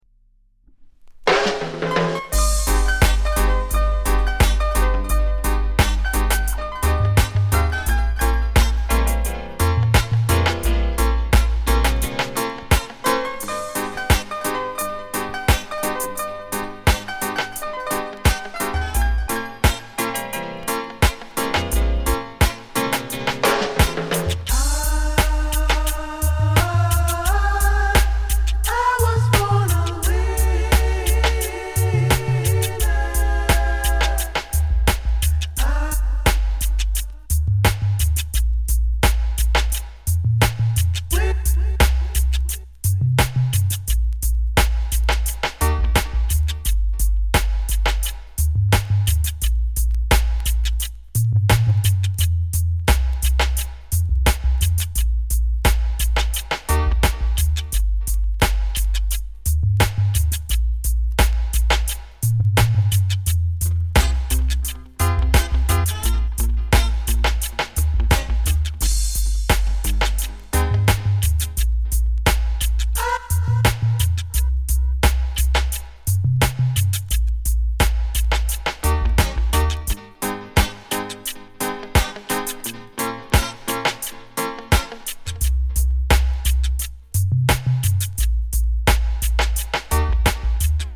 軽いチリ 乗りますが、気になるレベルではありません。